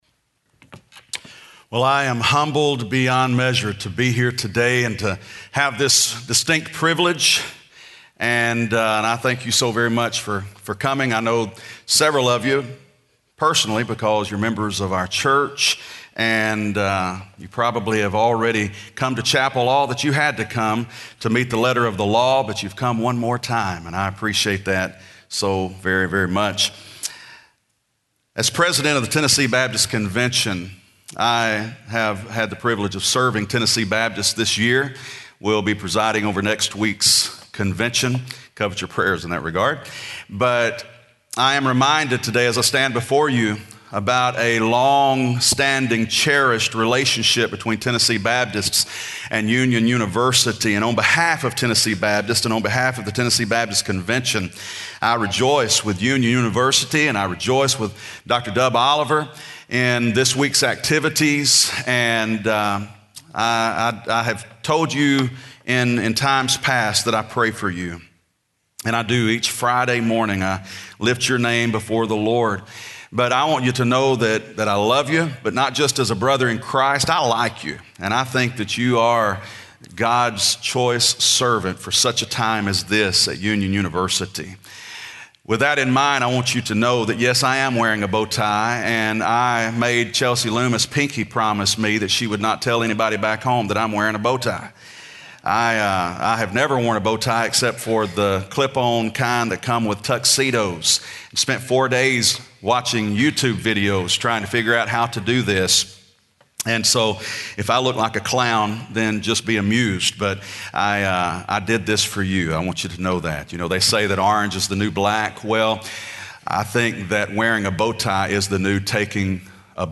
Chapel
Address: "CHRIST●ol●o●gy 101" from Colossians 1:15-23 Recording Date: Nov 5, 2014, 10:00 a.m. Length: 28:09 Format(s): MP3 ;